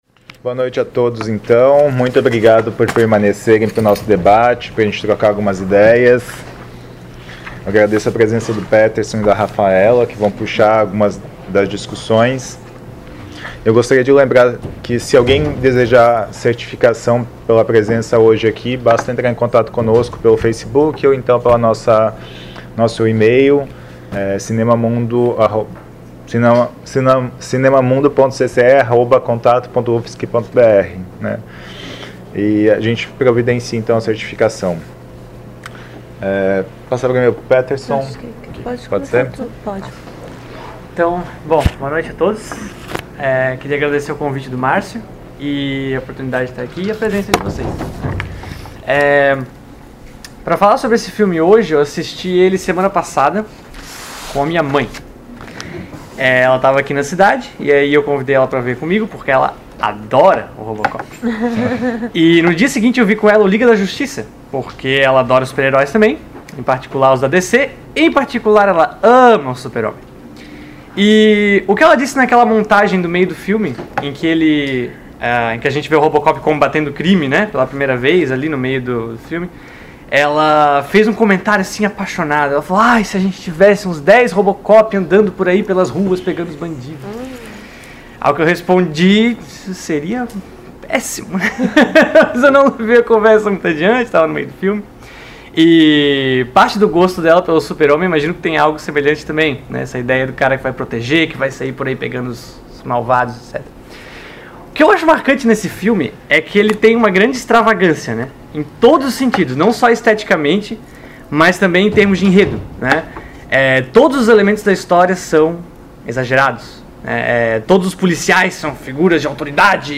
realizada em 16 de agosto de 2018 no Auditório "Elke Hering" da Biblioteca Central da UFSC
Debate